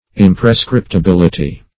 Search Result for " imprescriptibility" : The Collaborative International Dictionary of English v.0.48: Imprescriptibility \Im`pre*scrip`ti*bil"i*ty\, n. [Cf. F. imprescriptibilit['e].]
imprescriptibility.mp3